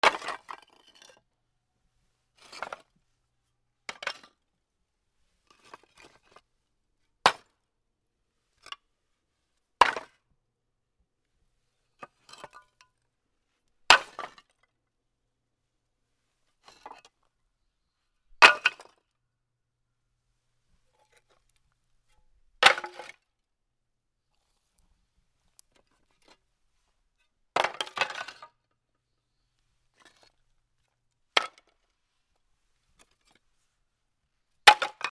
木を転がす
『カラン』